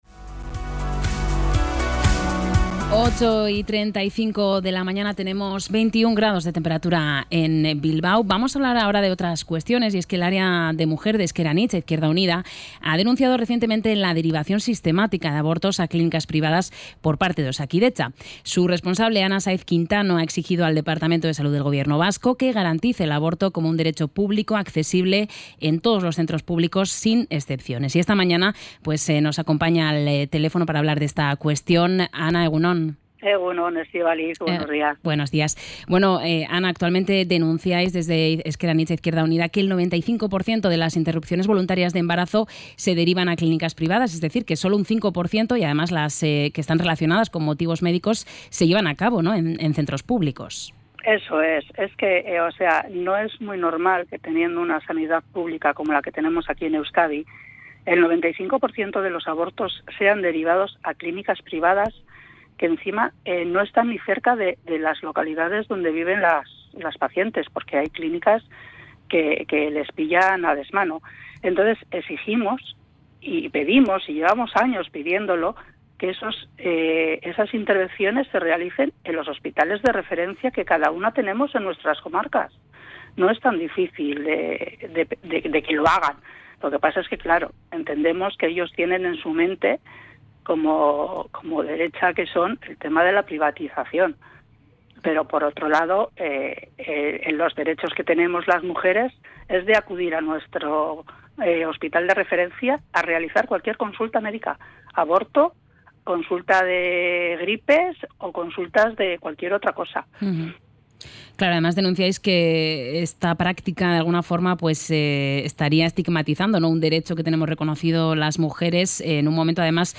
Podcast Sociedad